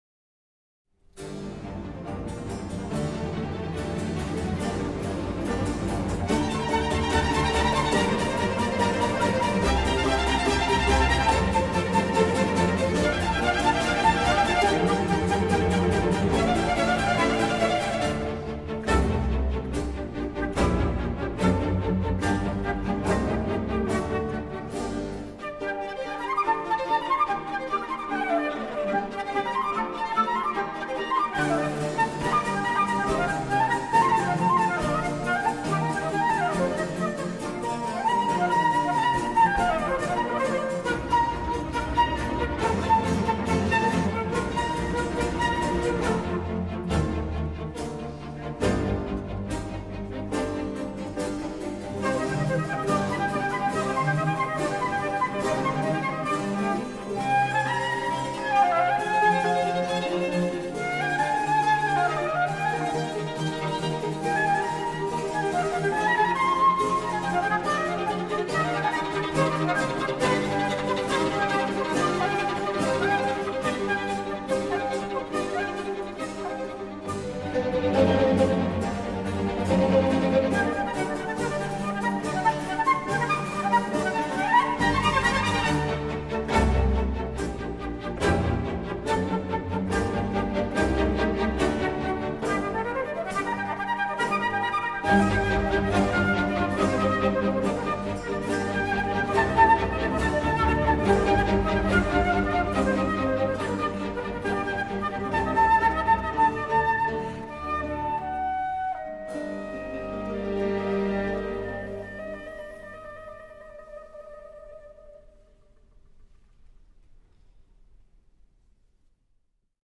Vivaldi - Flute Concerto - La notte.